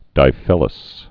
(dī-fĭləs)